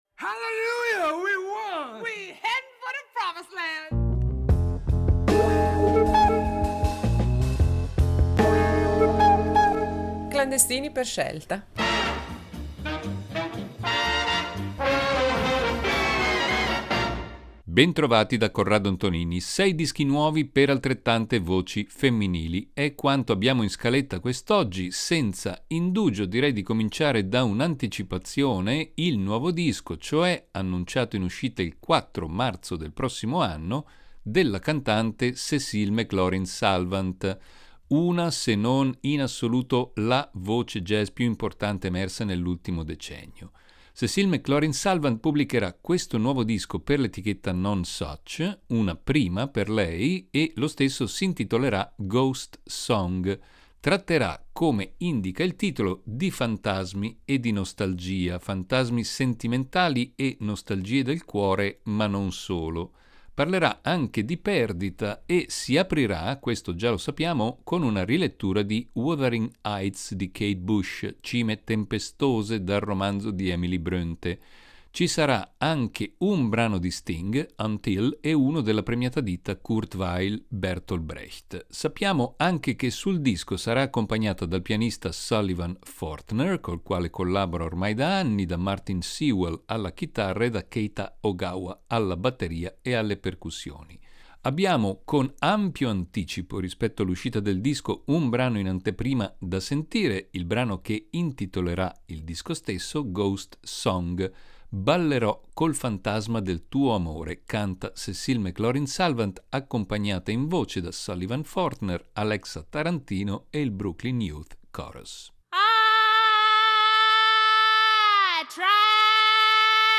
Sei dischi nuovi al centro dell’odierna puntata di “Clandestini per scelta”, e sei voci femminili.